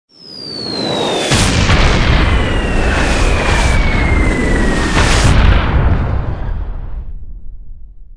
Tiếng bom Rơi và Nổ